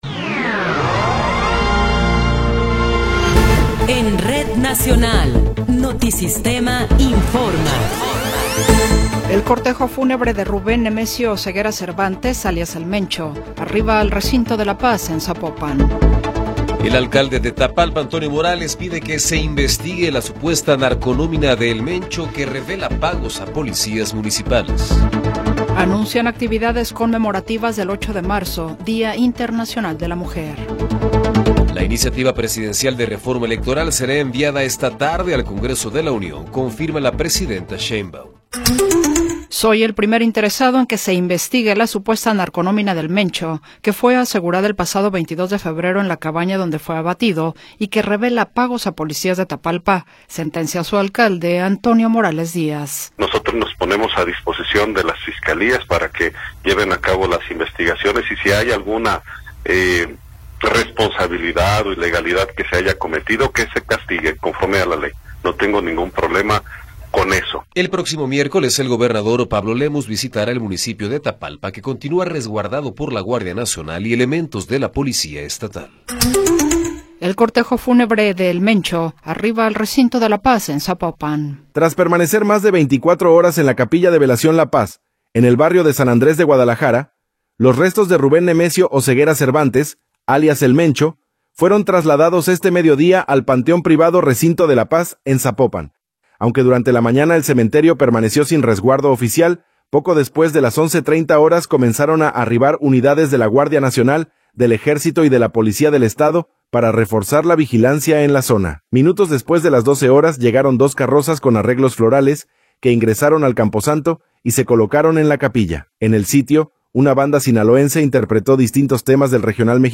Noticiero 14 hrs. – 2 de Marzo de 2026
Resumen informativo Notisistema, la mejor y más completa información cada hora en la hora.